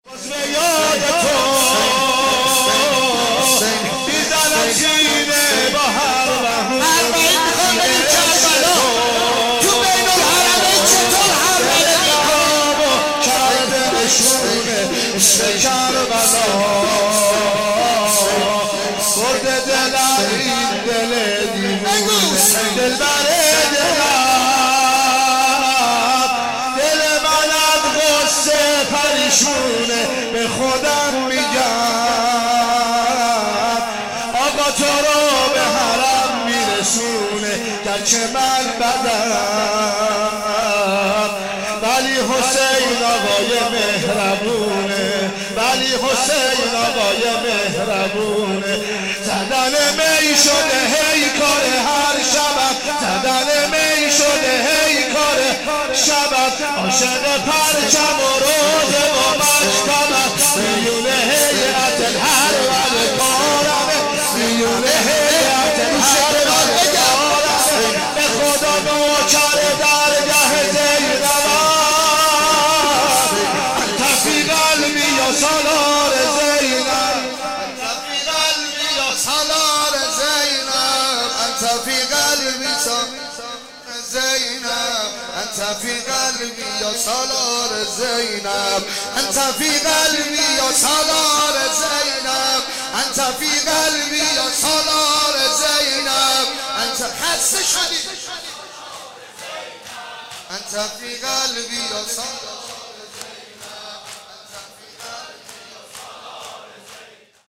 شب چهارم محرم 1395